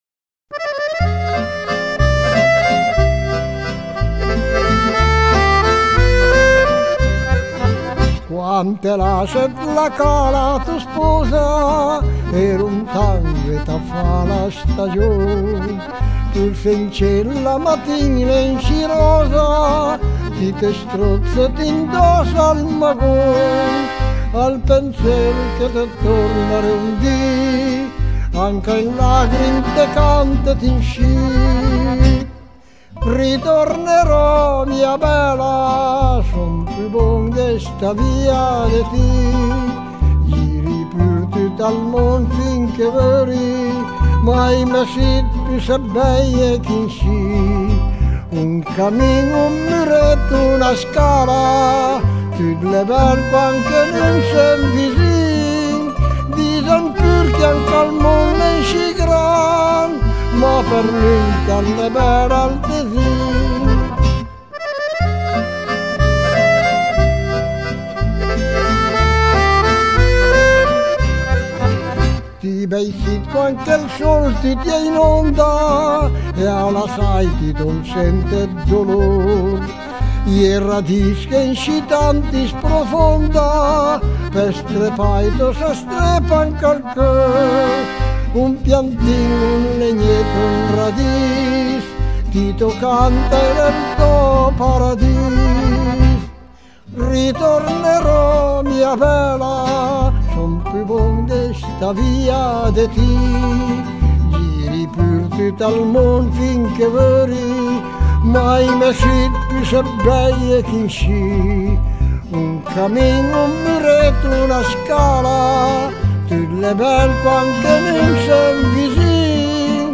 Un assaggio della musica popolare delle valli ticinesi e del Grigioni italiano e delle canzoni che hanno accompagnato l'epoca migratoria.